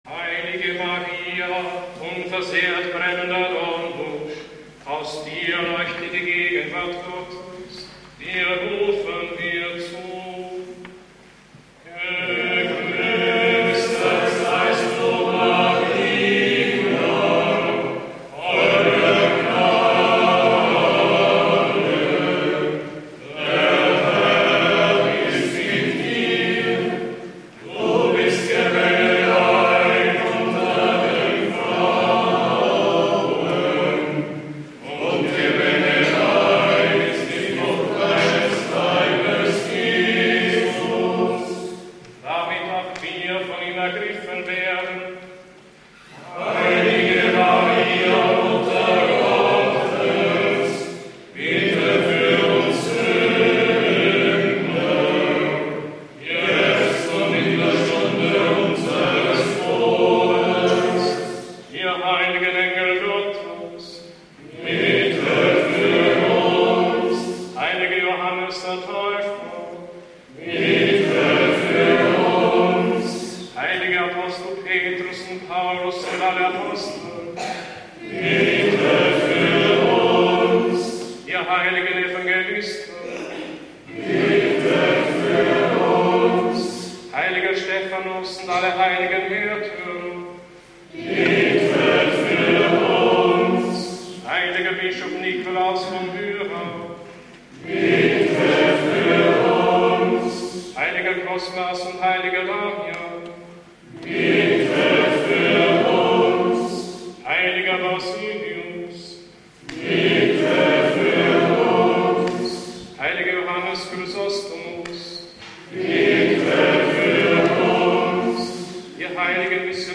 Für den Gebetsrahmen wählte der Gründer wegen seiner Ausdrucksstärke und seiner Erlernbarkeit den Kiewer Choral, wie er der liturgischen Praxis im bayerischen Kloster Niederaltaich (byzantinische Dekanie) zu Grunde liegt, hier mit strenger Terzparallele und Funktionsbass.